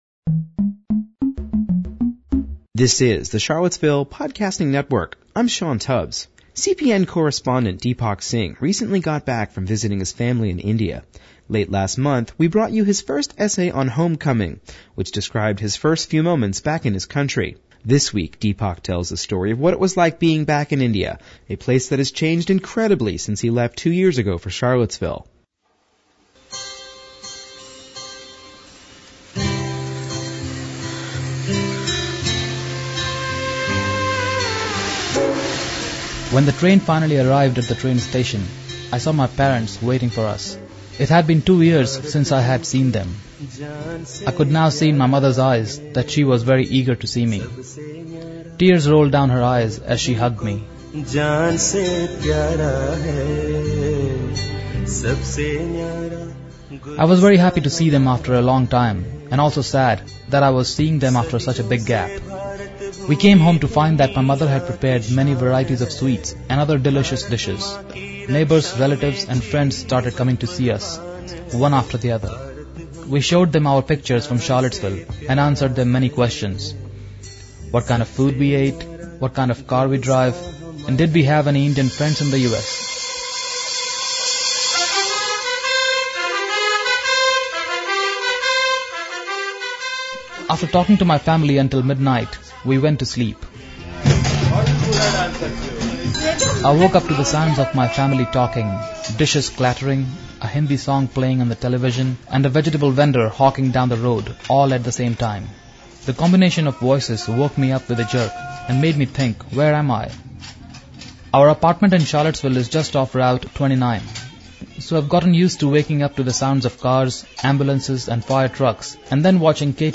Homecoming Part Two: An essay on returning to India